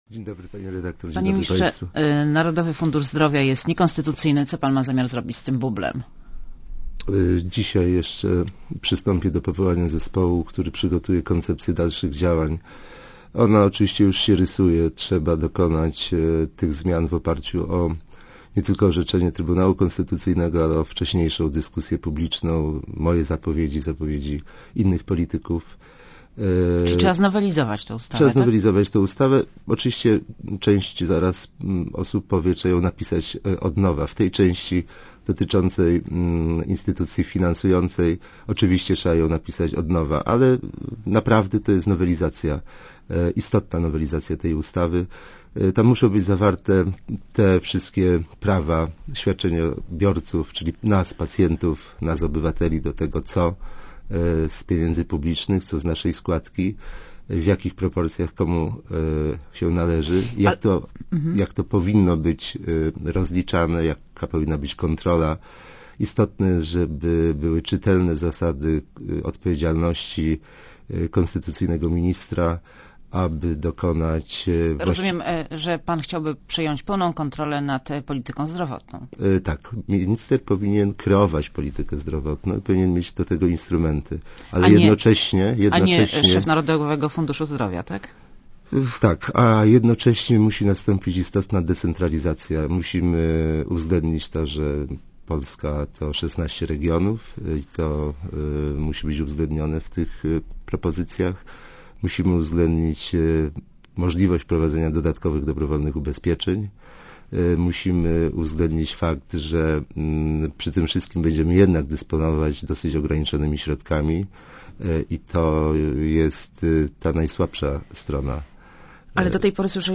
Leszek Sikorski w Radiu Zet (RadioZet)